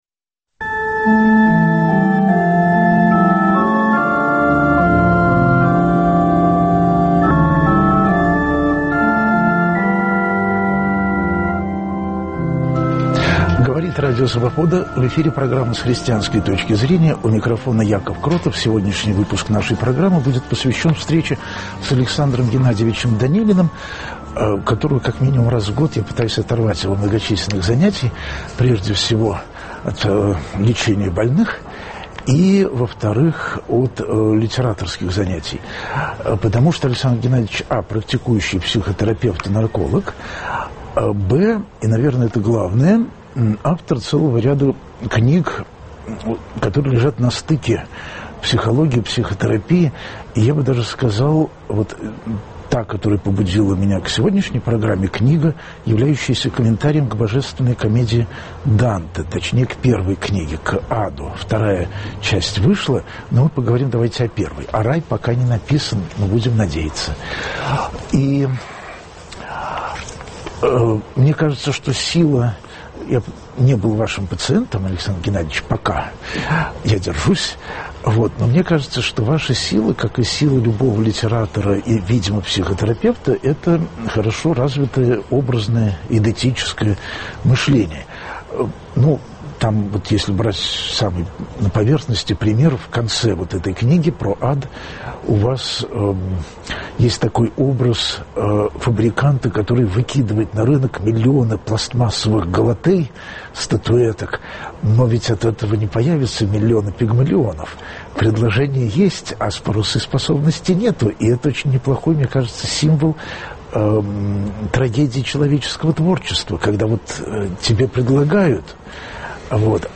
Существует ли ад? Ад, о котором говорит Христос, ад, о котором говорит Данте, ад, которого не хотят все хорошие люди, - один и тот же или разные? Об этом в программе разговор с психологом